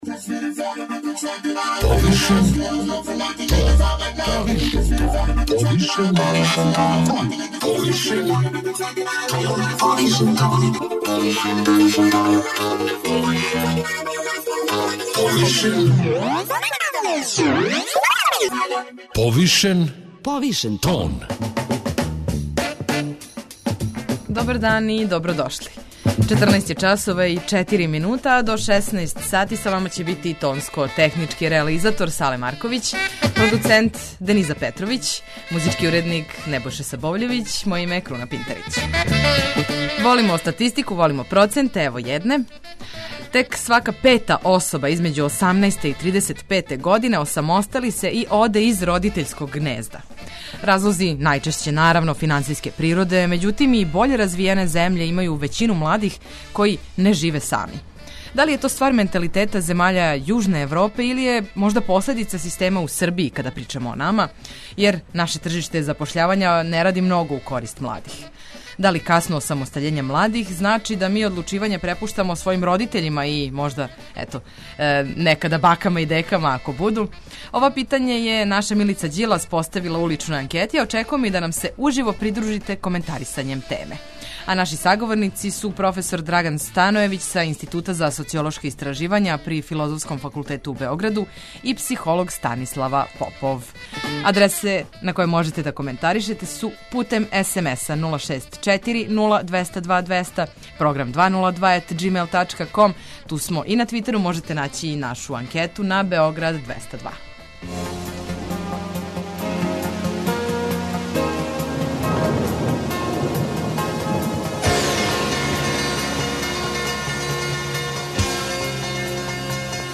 а очекујемо и да нам се уживо придружите коментарисањем теме.